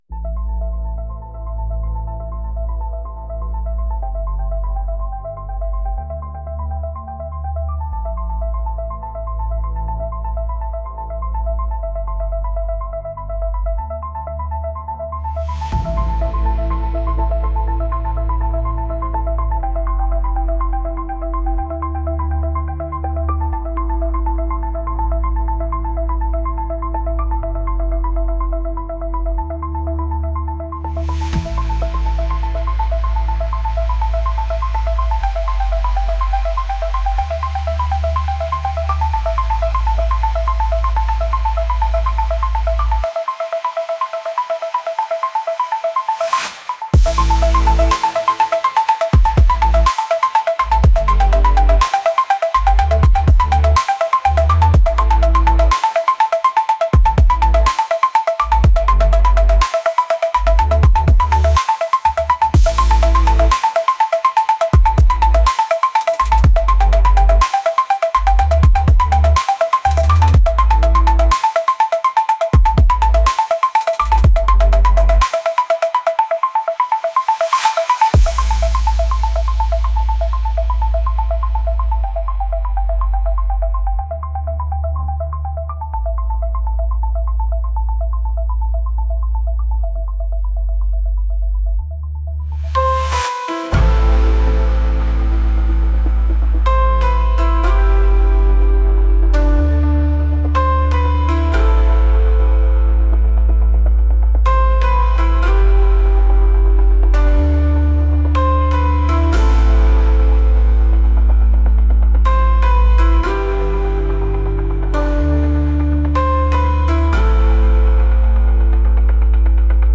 • Звук. В Suno создали джингл, который добавил динамики уроку и был использован в качестве перебивок между разделами курса.
Бит и шум воды (1).mp3
bit_i_shum_vody_1.mp3